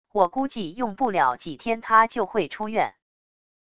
我估计用不了几天他就会出院wav音频生成系统WAV Audio Player